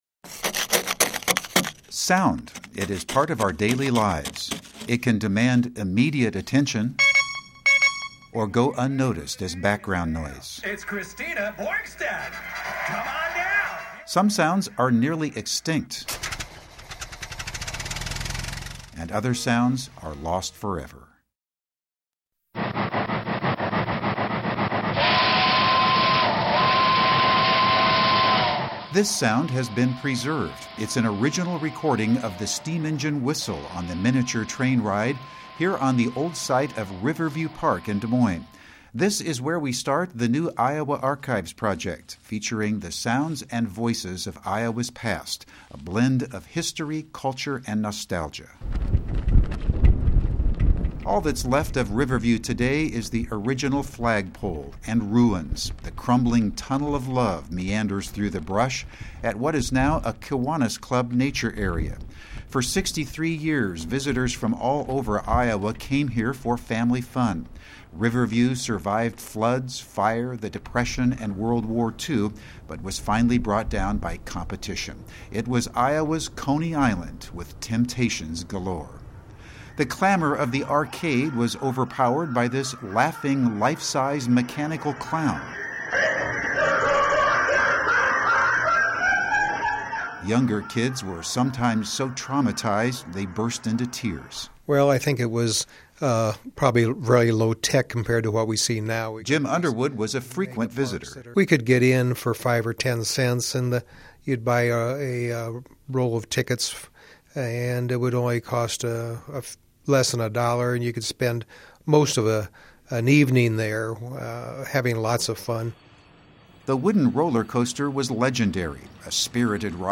Kiwanis Riverview Nature Island ...Photos of Riverview Park land today Iowa Public Radio Iowa Archives Click to HERE Listen. .....Iowa Archives: Sounds from Iowa's past featuring sounds from pre-1978, Riverview Park, Des Moines. Original sounds from the arcade, the steam engine train and the Riviera Ballroom.